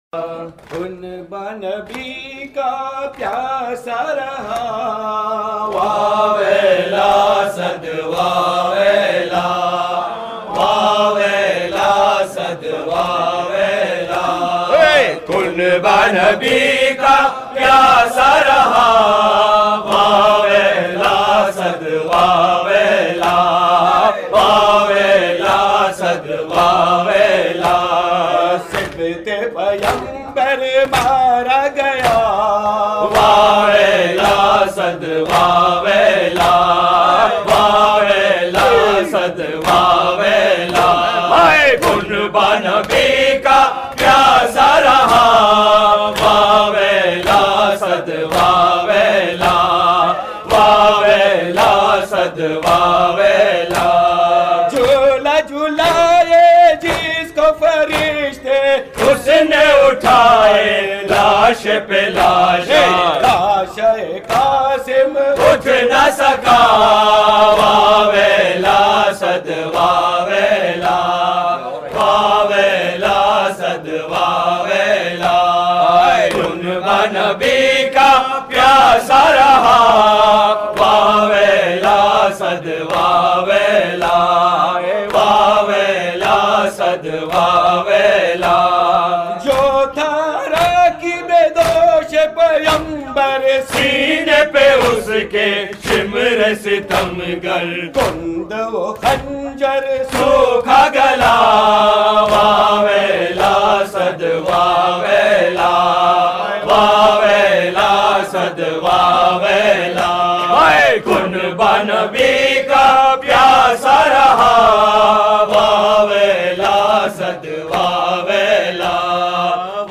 Non Saff Calssic Marsia / Nawha